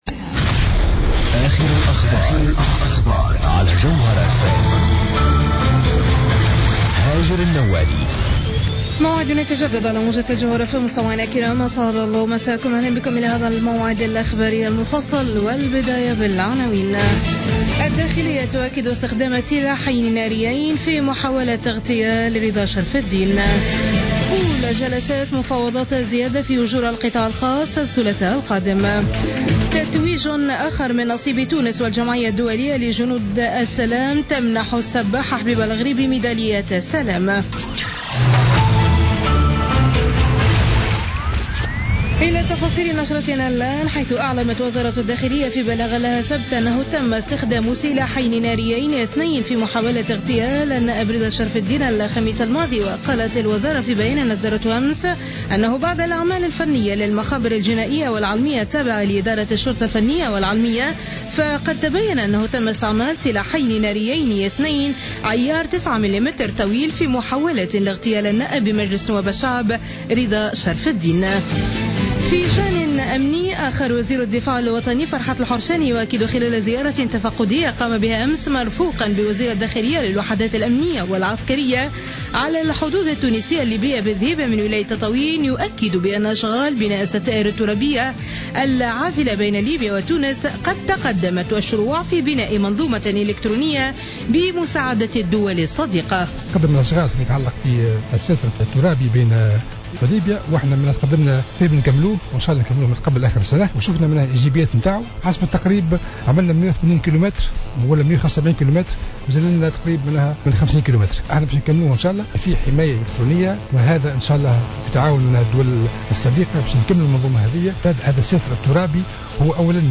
نشرة أخبار منتصف الليل ليوم الأحد 11 أكتوبر 2015